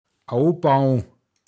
pinzgauer mundart
abbauen åobau(n)